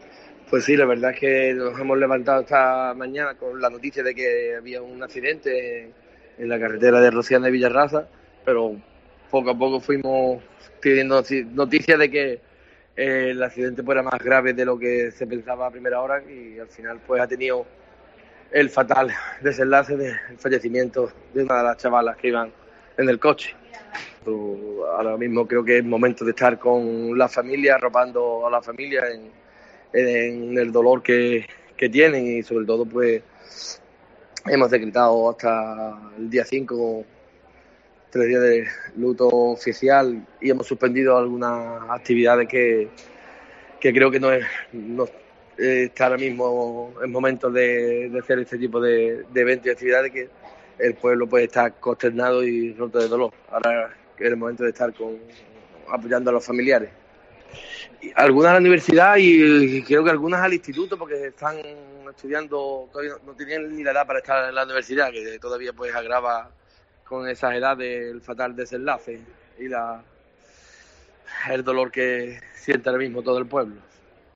El alcalde de Rociana del Condado, Diego Pichardo, atiende a COPE Huelva para lamentar el terrible accidente de esta mañana en el que falleció una menor.